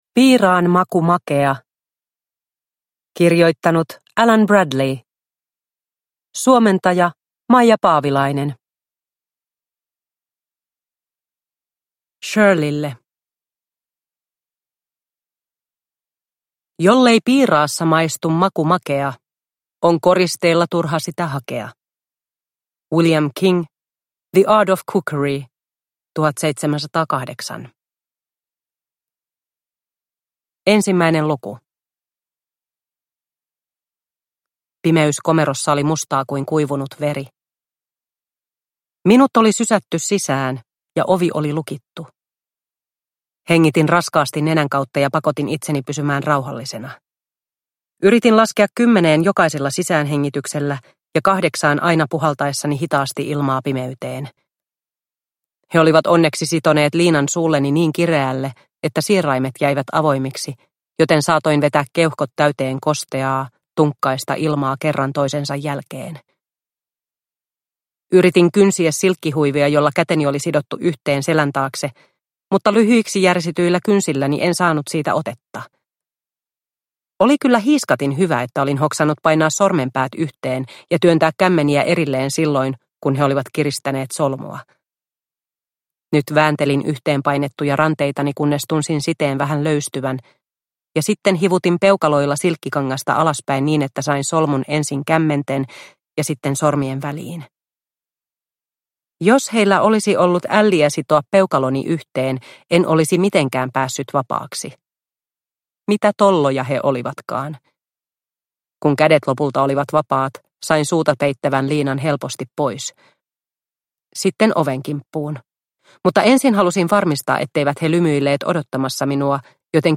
Piiraan maku makea – Ljudbok – Laddas ner